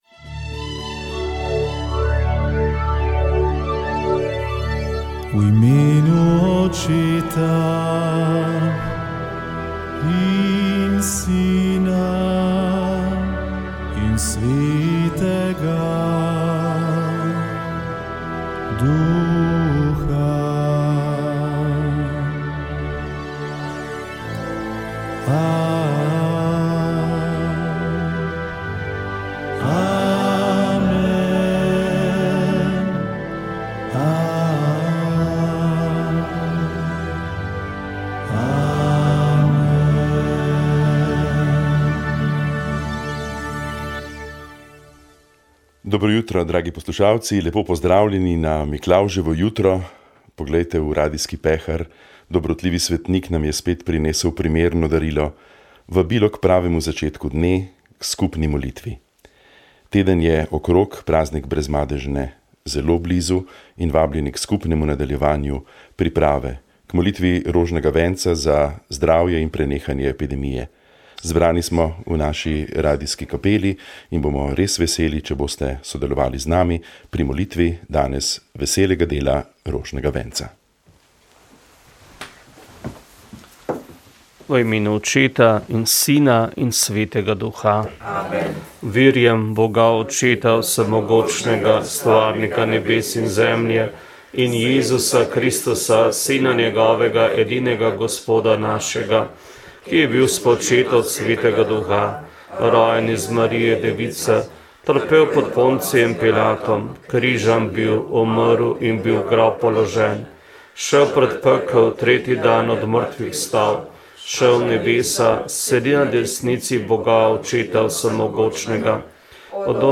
Molili so radijski sodelavci.